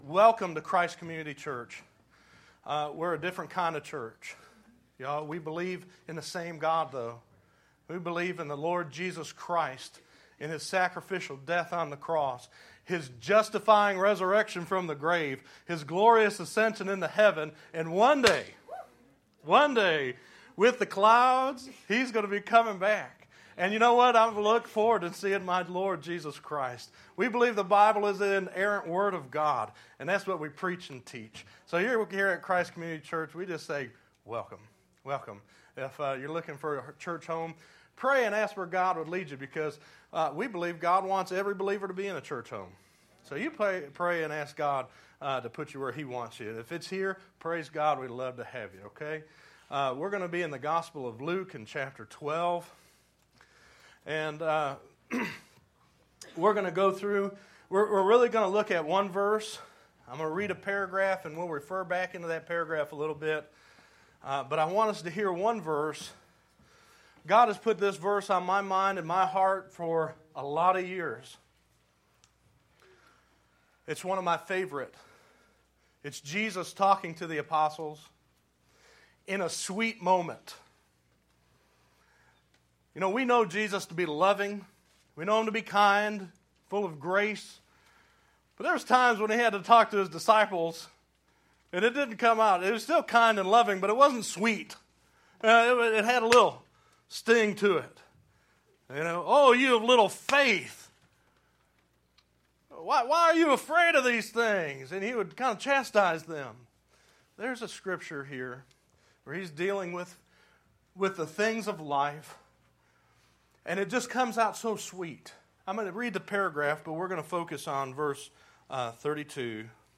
9_16_12_Sermon.mp3